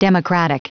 Prononciation du mot democratic en anglais (fichier audio)
Prononciation du mot : democratic